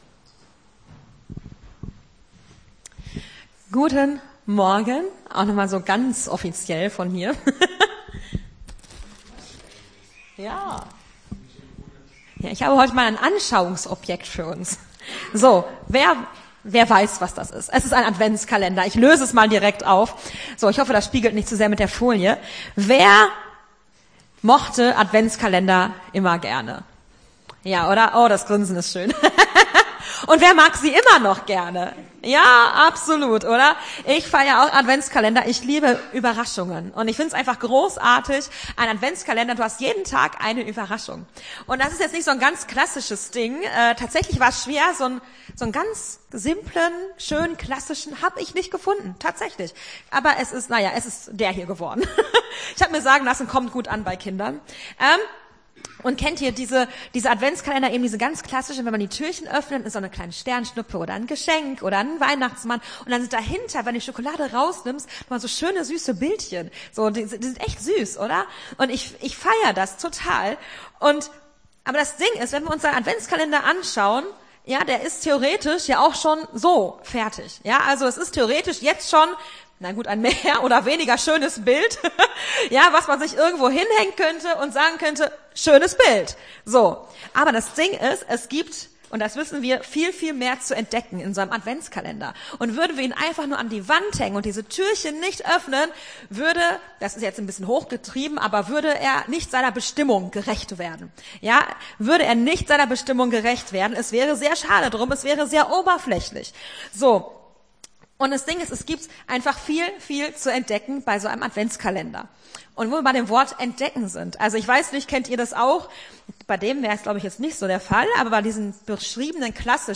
Gottesdienst 04.12.22 - FCG Hagen